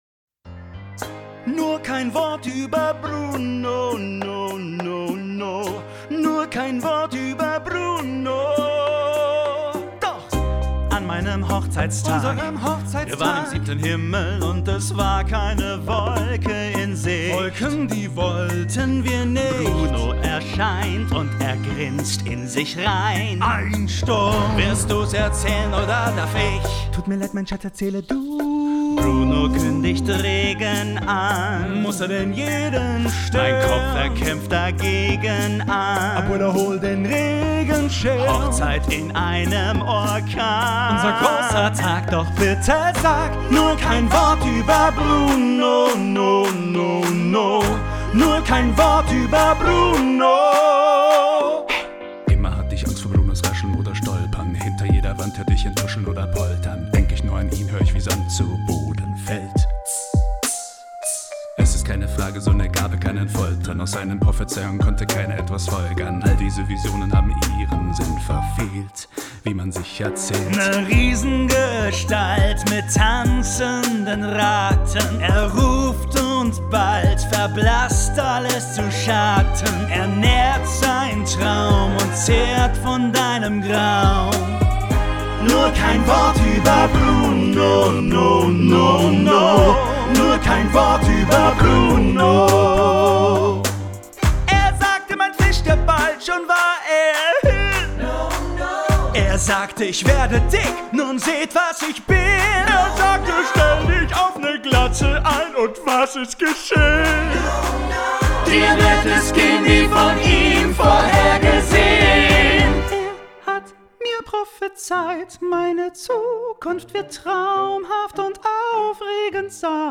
GESANG